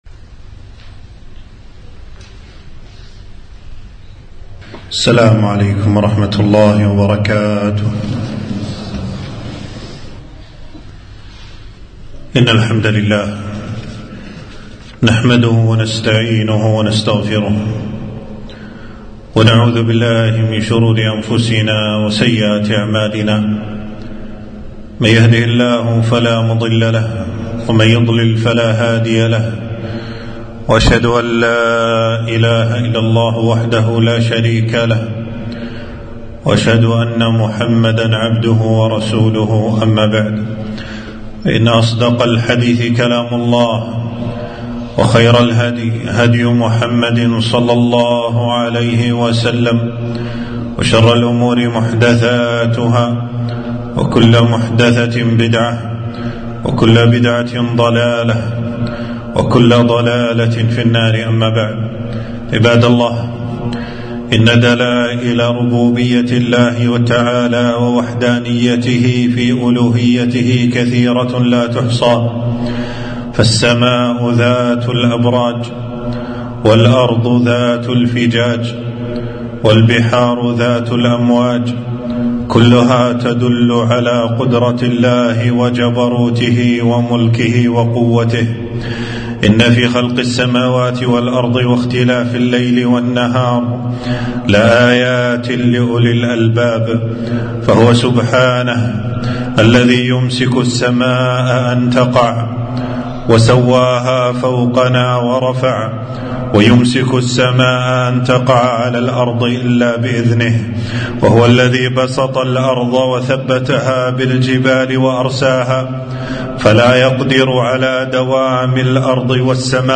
خطبة - وما نرسل بالآيات إلا تخويفا